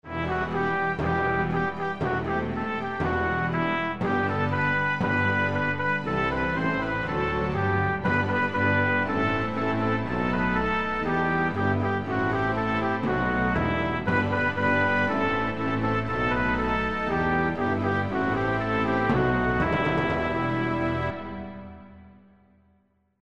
National anthem: "Nad Tatrou sa blýska, hromy divo bijú"